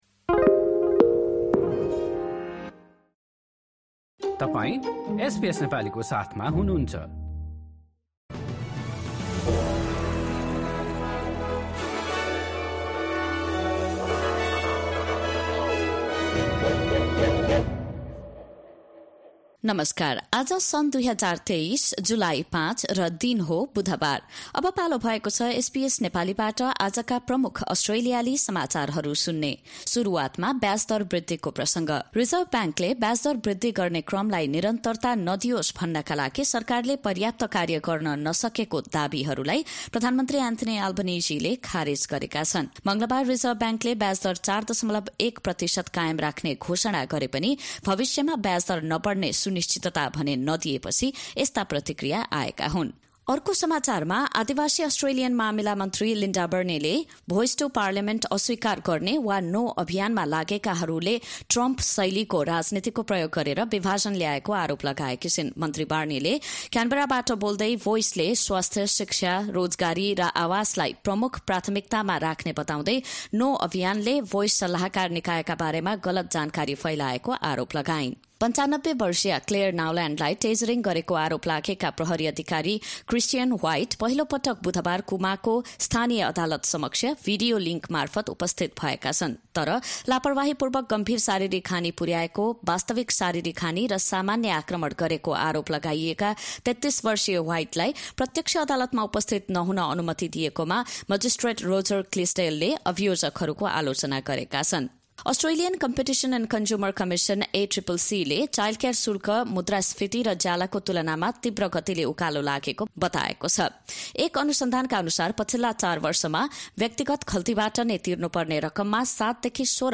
एसबीएस नेपाली प्रमुख अस्ट्रेलियाली समाचार: बुधवार, ५ जुलाई २०२३